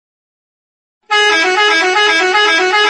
truckhoran.mp3